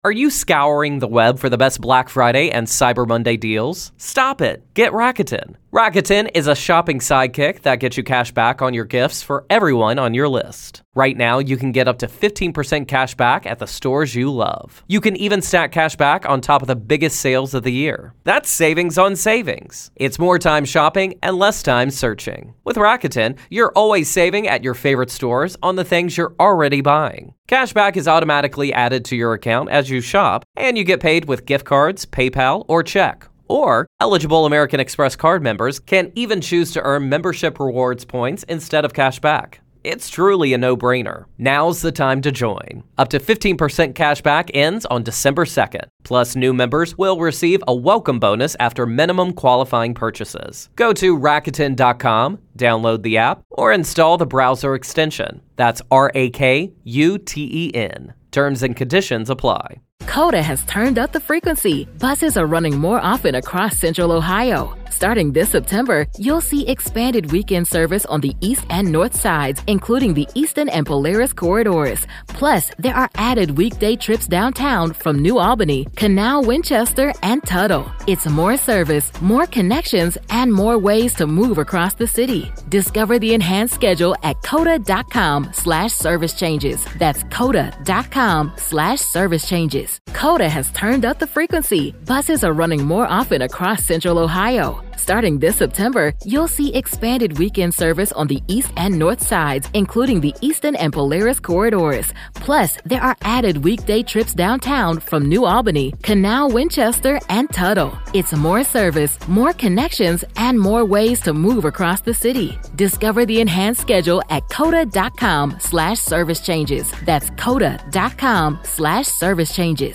One conversation that lays out the stakes, the law, and the fallout.